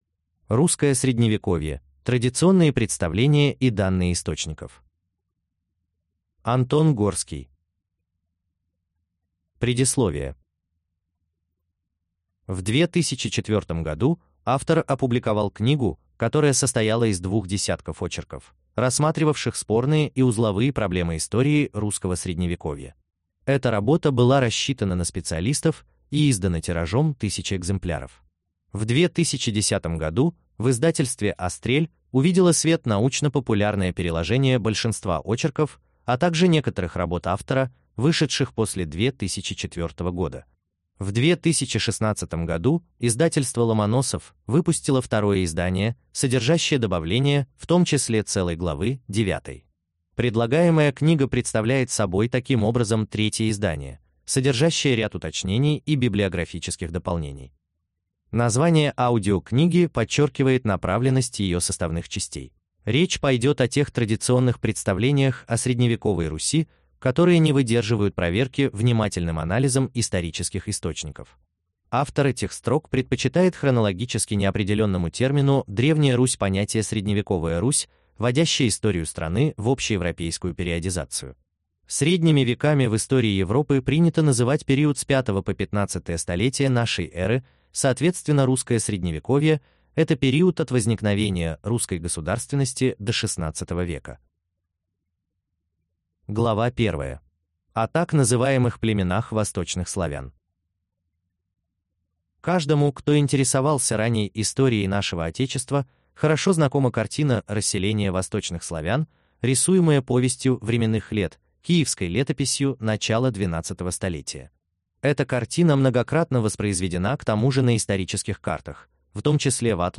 Аудиокнига Русское Средневековье.